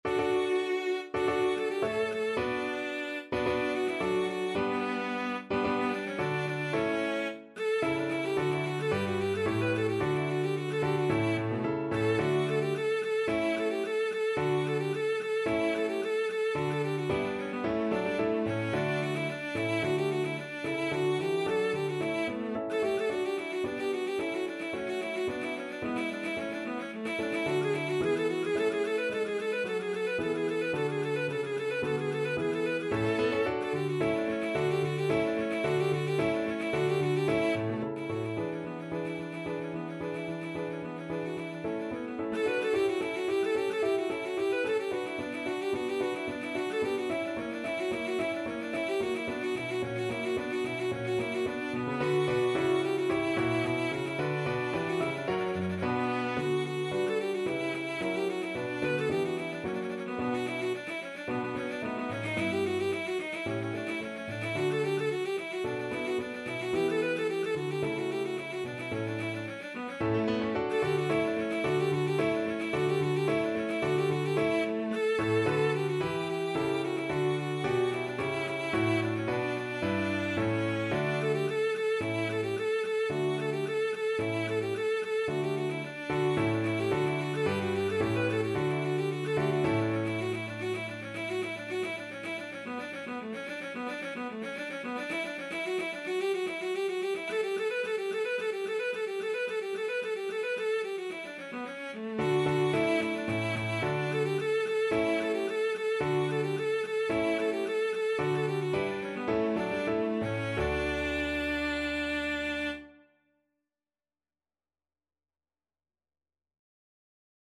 Free Sheet music for Cello
Cello
D major (Sounding Pitch) (View more D major Music for Cello )
~ = 110 I: Allegro (View more music marked Allegro)
4/4 (View more 4/4 Music)
Classical (View more Classical Cello Music)
vivaldi_op3_9_1st_VLC.mp3